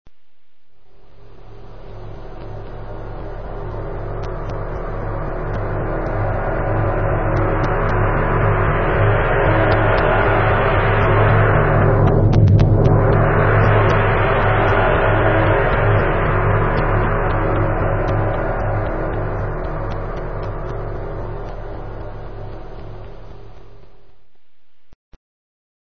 Descarga de Sonidos mp3 Gratis: espacio 9.
space1.mp3